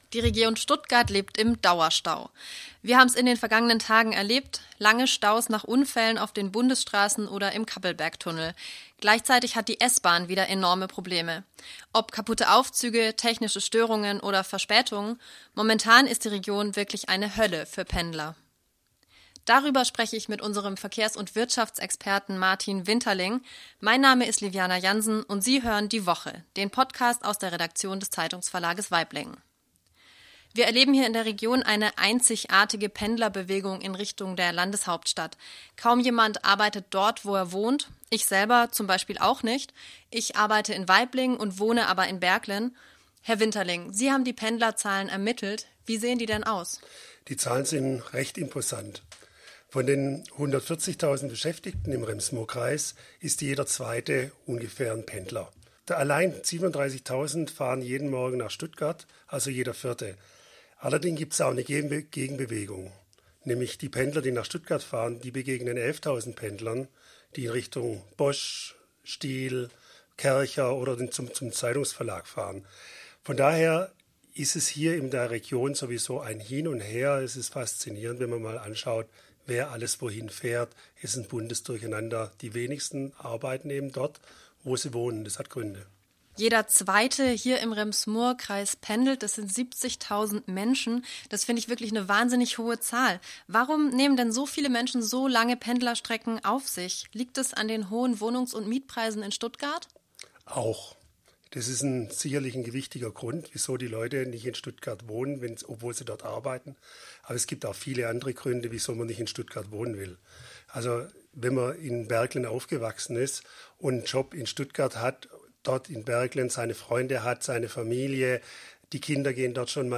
diskutieren mit einem Gesprächspartner über aktuelle Themen aus dem Rems-Murr-Kreis.